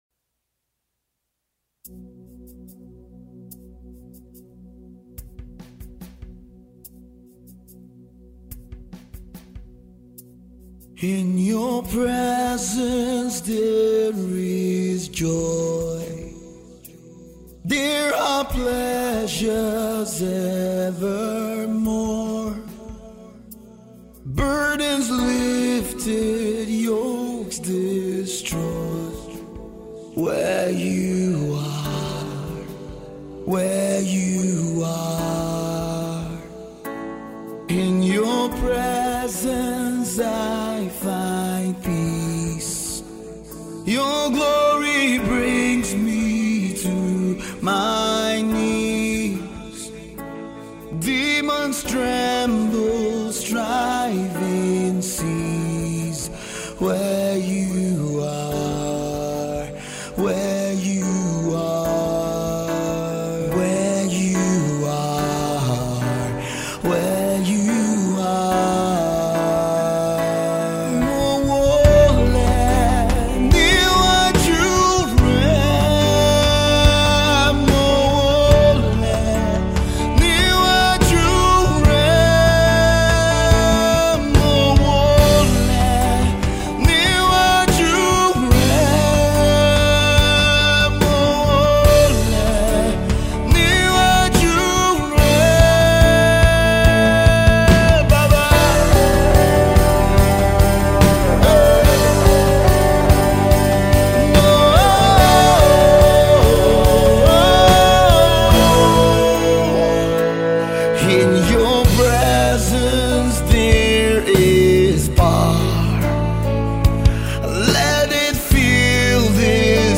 Contemporary singer